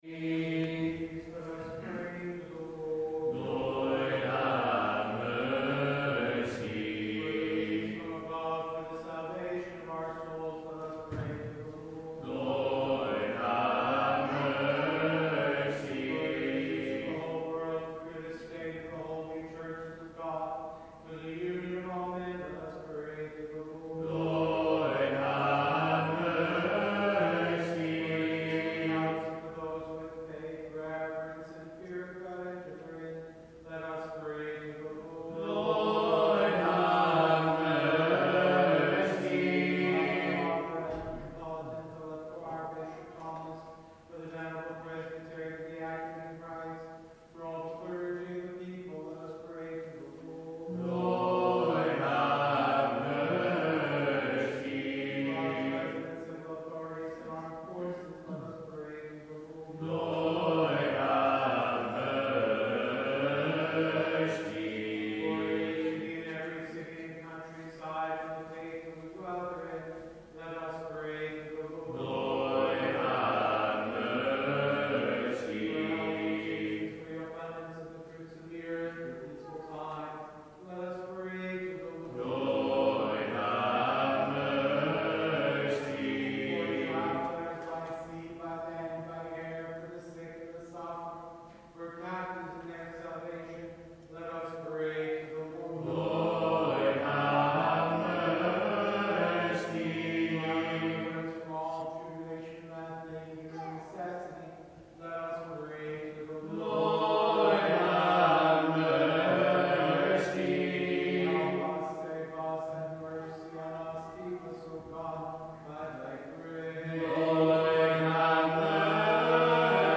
Recordings of our Byzantine Choir
Live Recordings from Services